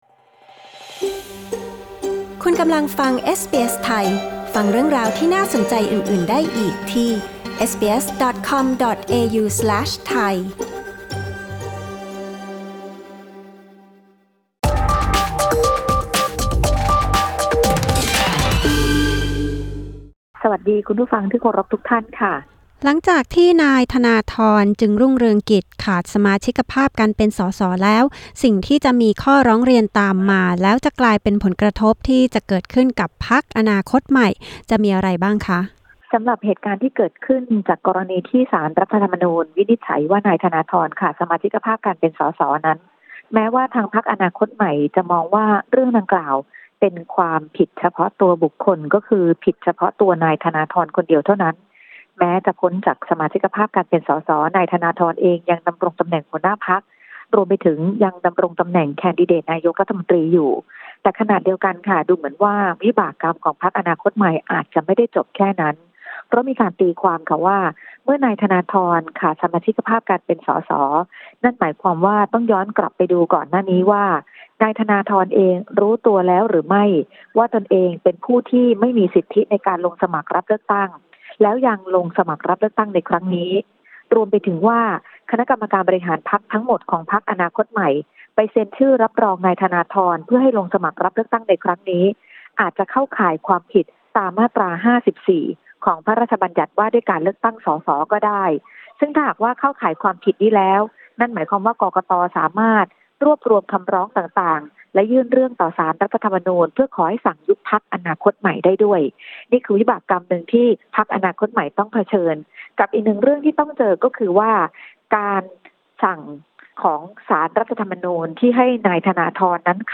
กดปุ่ม 🔊 ด้านบนเพื่อฟังรายงานข่าว